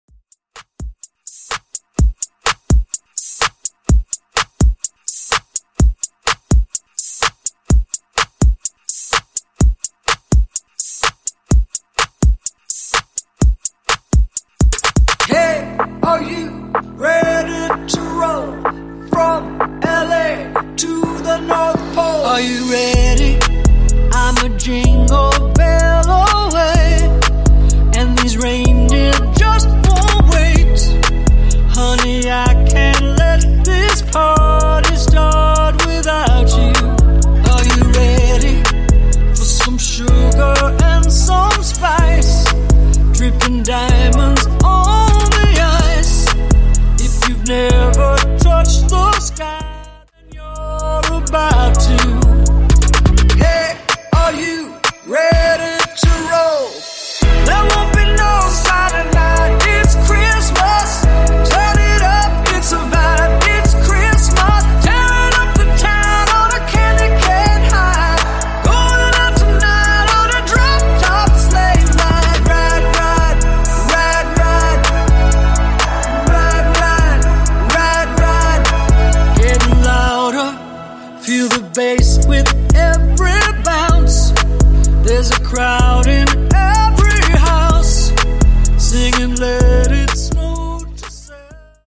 Genre: ROCK
Clean BPM: 68 Time